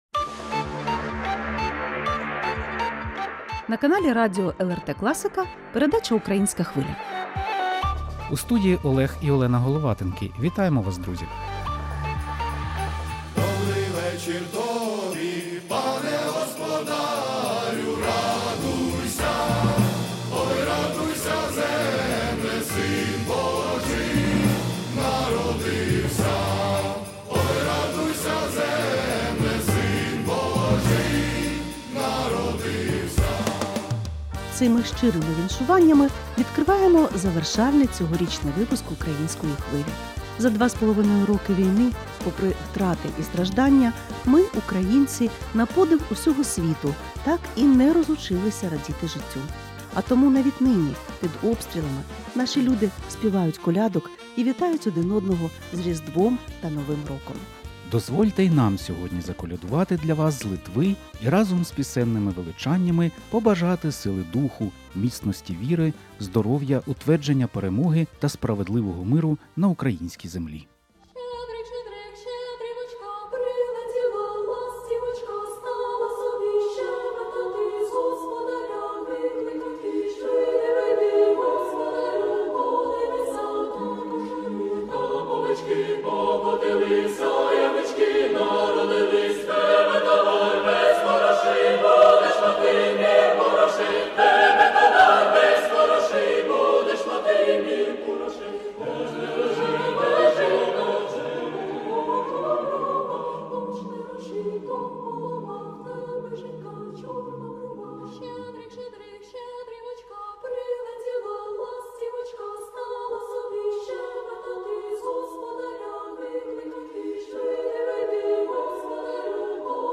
У передачі звучать давні автентичні та сучасні авторські українські колядки у виконанні бардів та популярних співаків і гуртів.